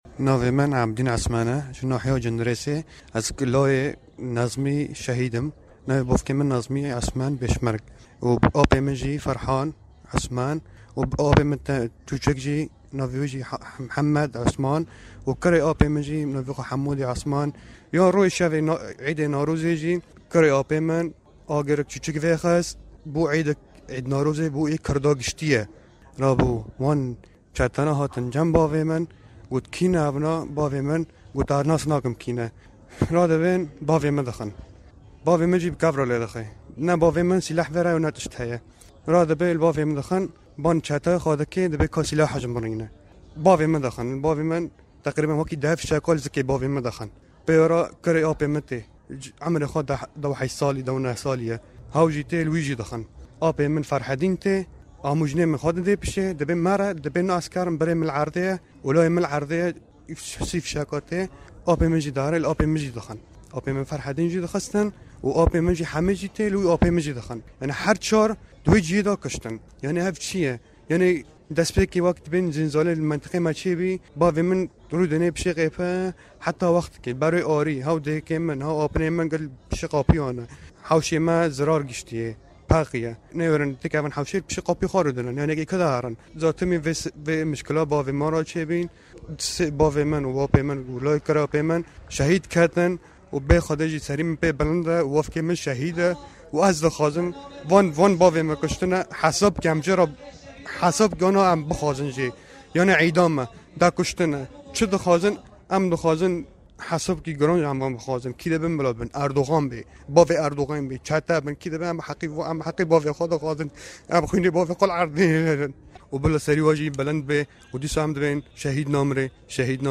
لێدوانی کەسوکاری قوربانییەکان